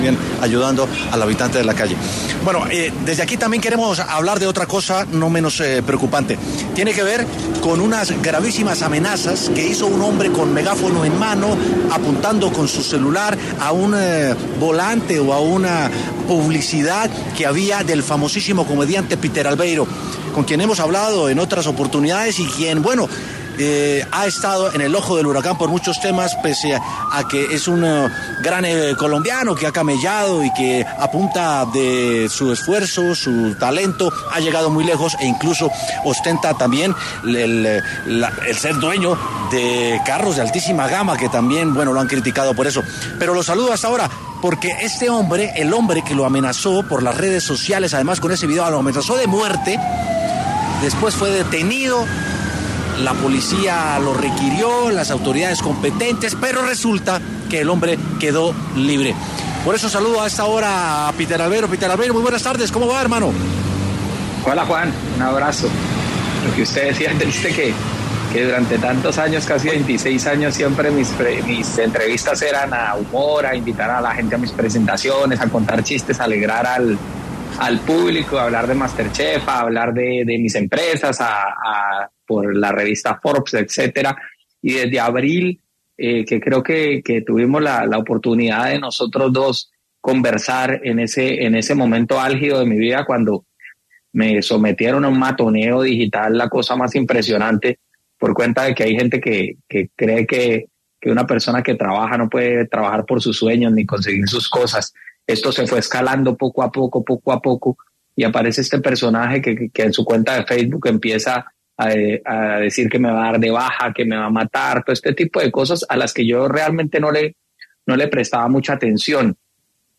El humorista Piter Albeiro, conversó con W Sin Carreta, sobre las amenazas que recibió y cómo fue el accionar de la justicia frente al caso.